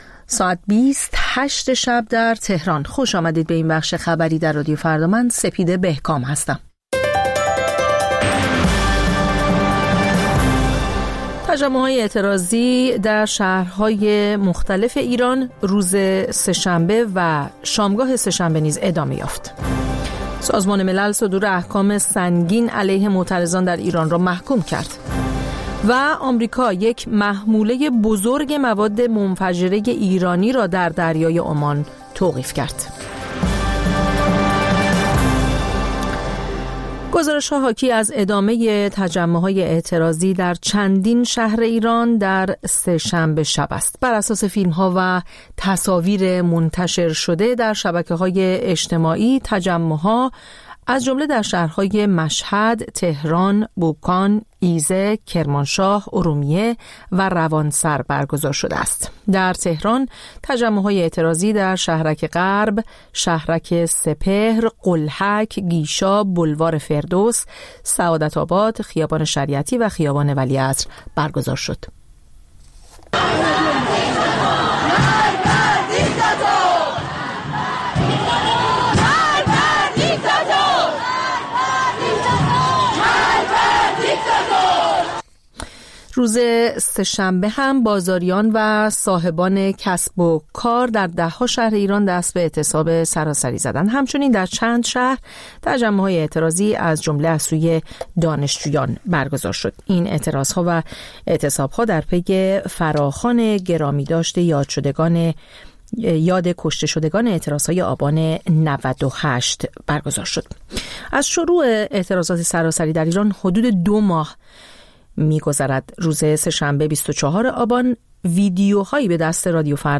خبرها و گزارش‌ها ۲۰:۰۰